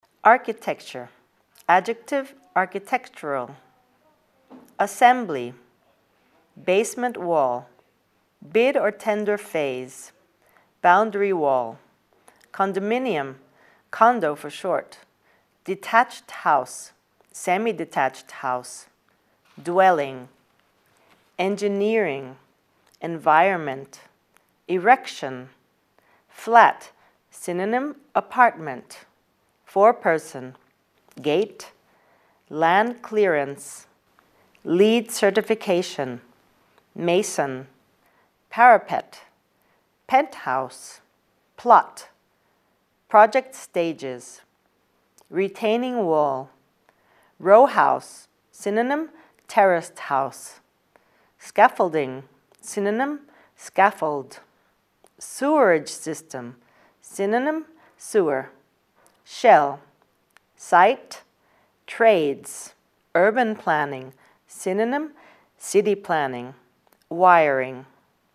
Terms to study and audio with the pronunciation